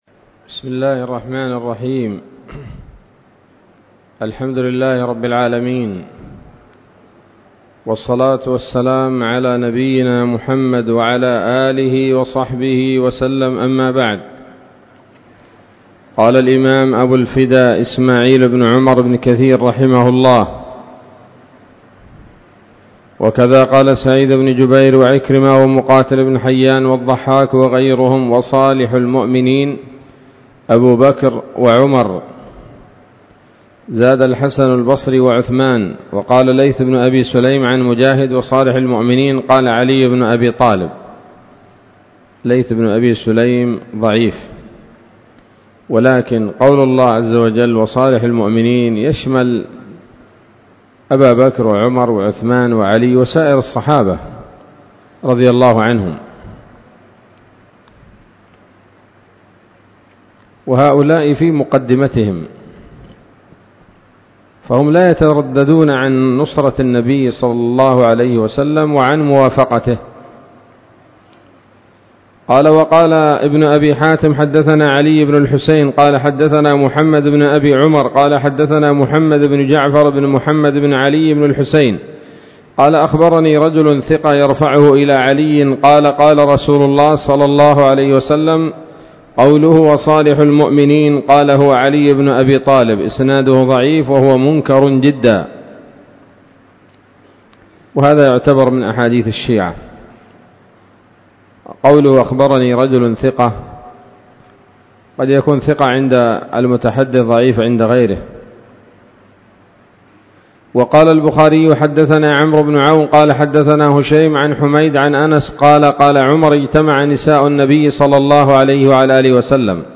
الدرس الثالث من سورة التحريم من تفسير ابن كثير رحمه الله تعالى